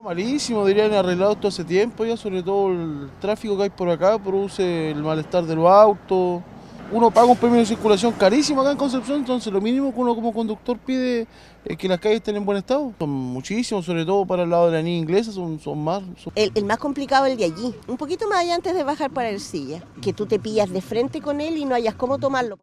Radio Bío Bío de Concepción conversó con conductores que transitan por el tramo de avenida Pedro de Valdivia.
cuna-pedro-valdivia-conductores.mp3